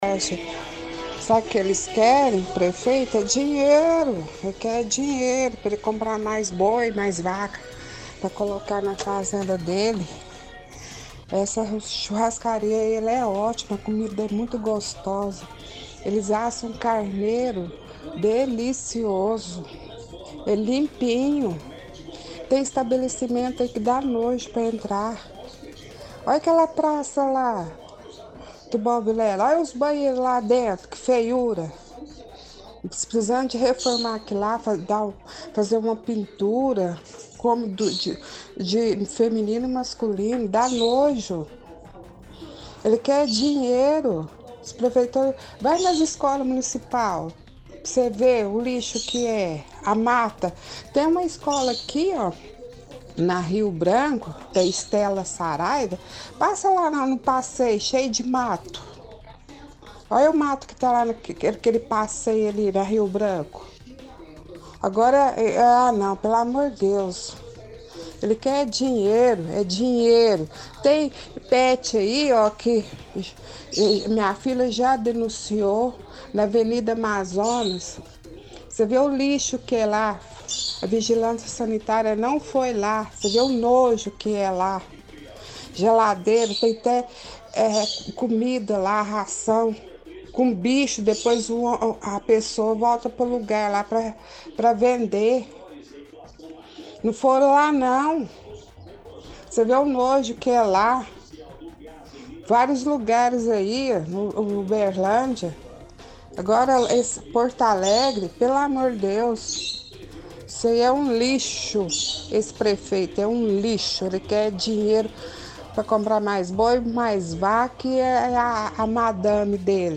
Ligação Ouvintes – Criticam Vigilância Sanitária